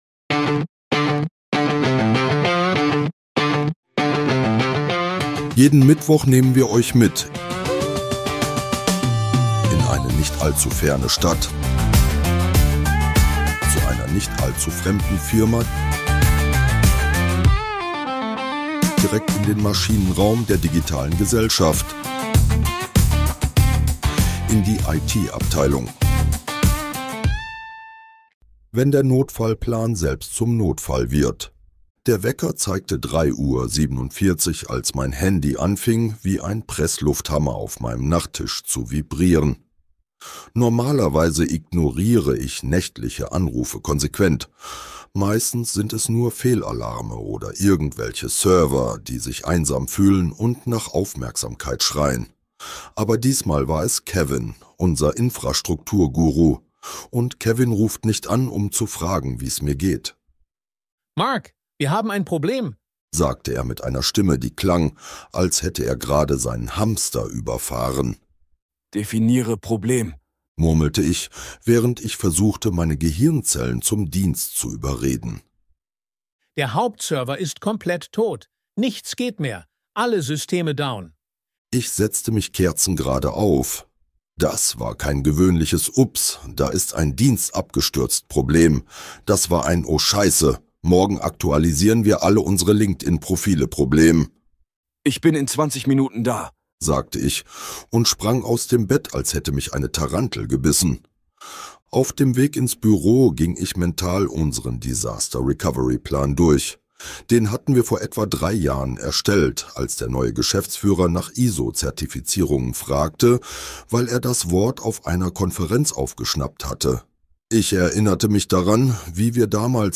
Dieser Podcast ist Comedy.
(AI generiert) Mehr